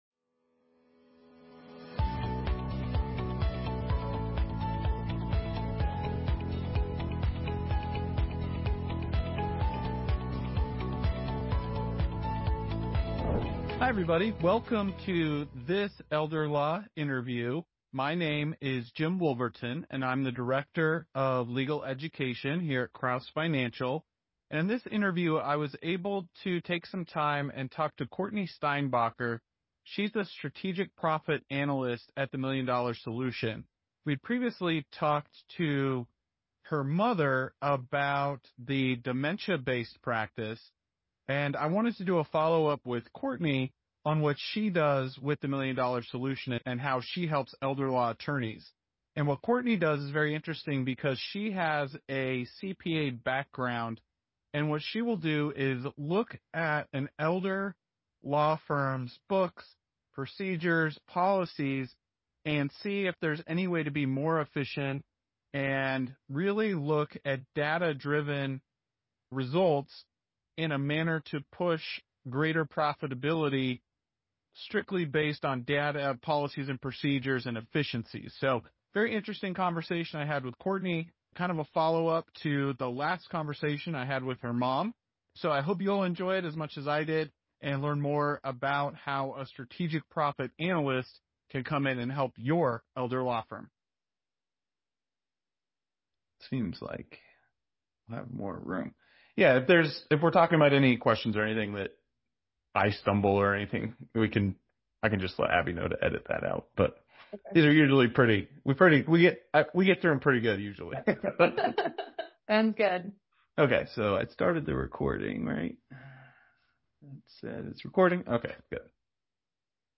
Download Audio Version In this Elder Law Interview